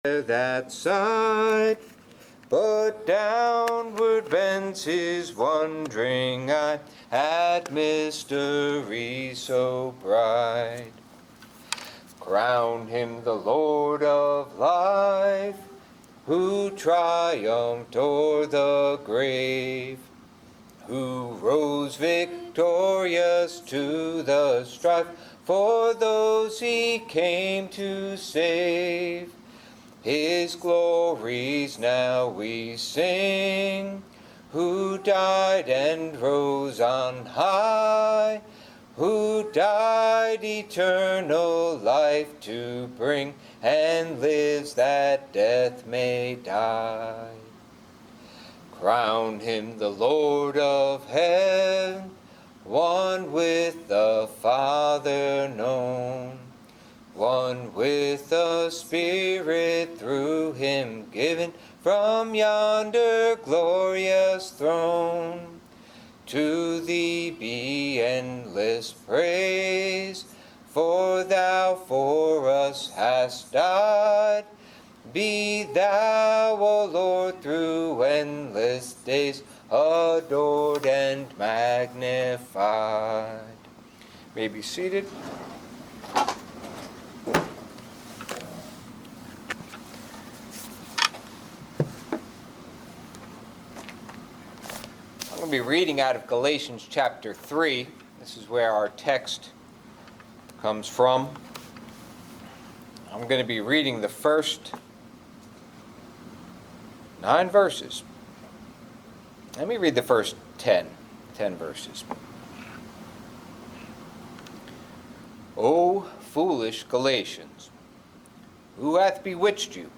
Blessed With Faithful Abraham | SermonAudio Broadcaster is Live View the Live Stream Share this sermon Disabled by adblocker Copy URL Copied!